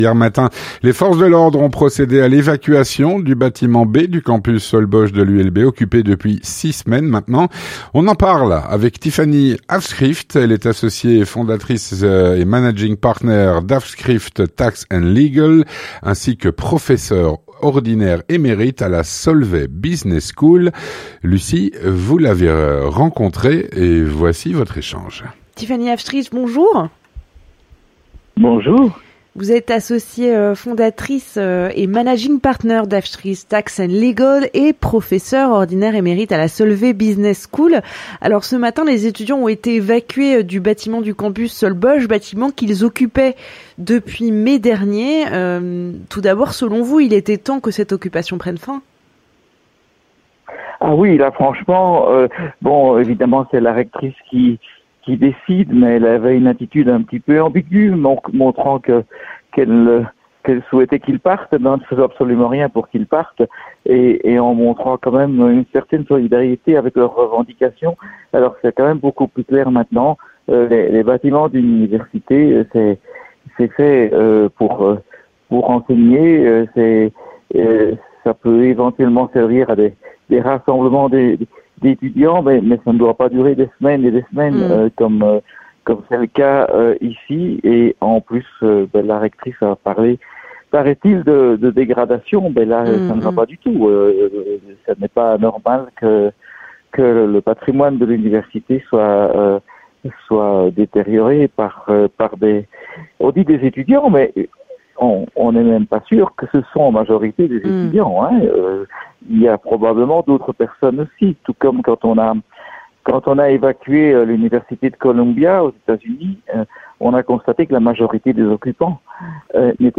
L'entretien du 18H - les forces de l’ordre ont procédé à l’évacuation du bâtiment B du campus Solbosch de l’ULB, occupé depuis 6 semaines.